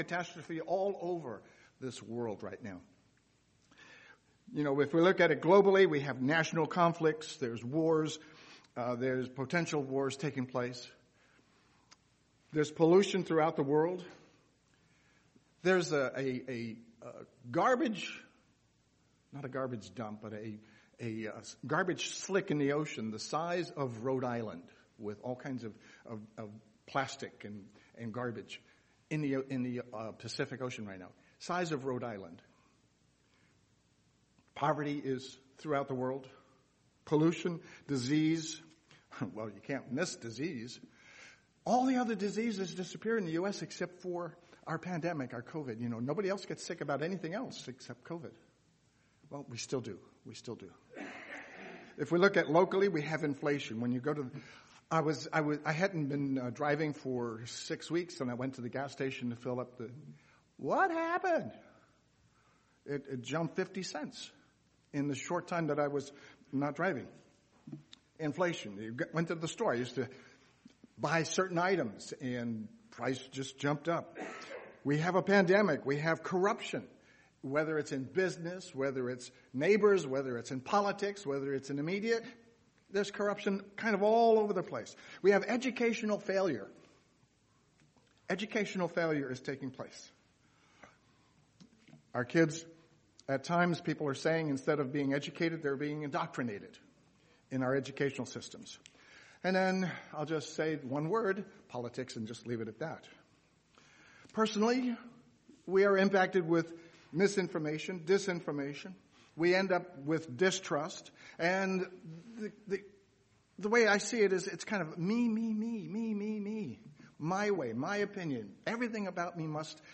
Sermons
Given in Eureka, CA